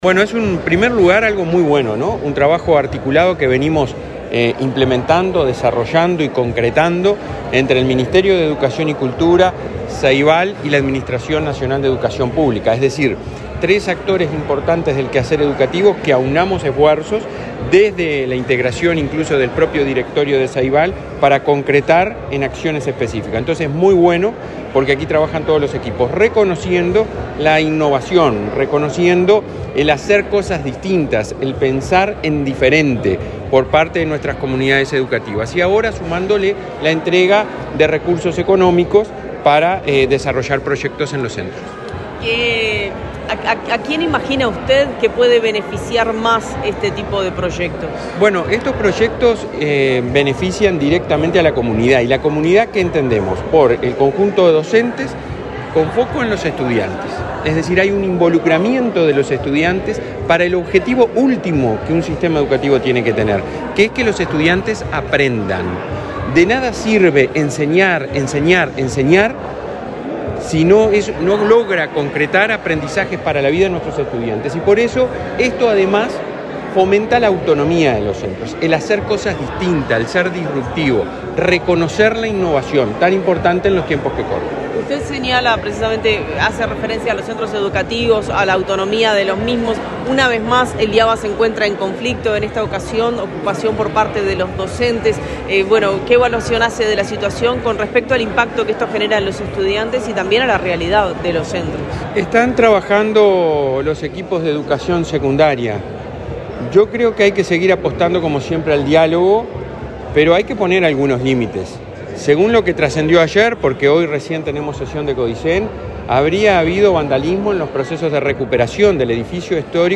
Declaraciones del presidente de la ANEP, Robert Silva
El presidente de la Administración Nacional de Educación Pública (ANEP), Robert Silva, dialogó con la prensa, antes de participar de participar en el